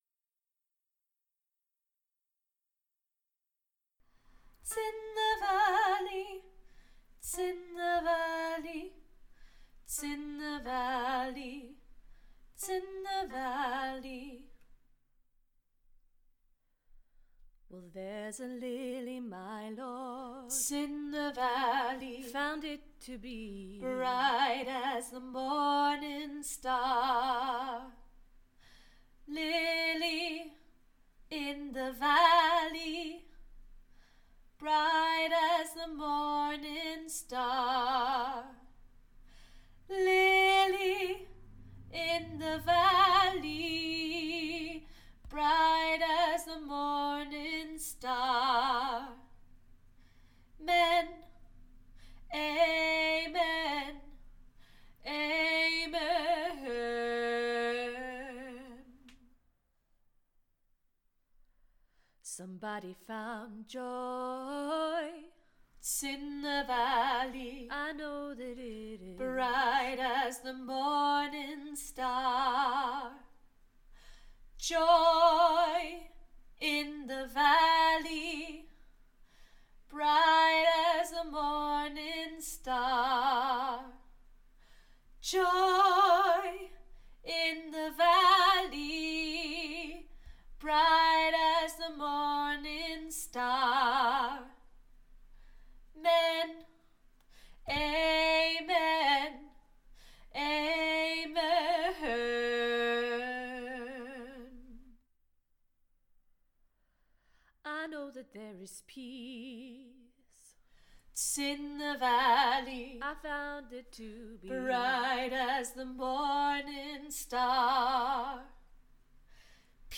Lily In The Valley Tenor
Lily-In-The-Valley-Tenor.mp3